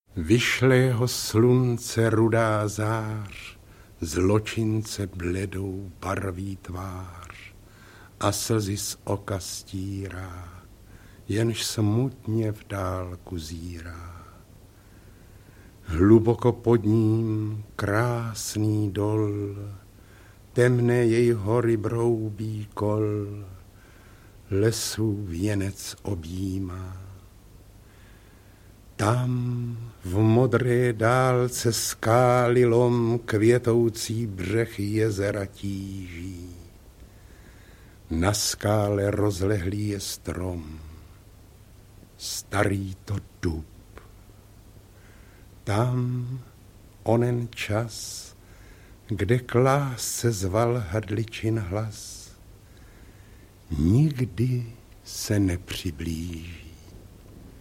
Máj - úryvek audiokniha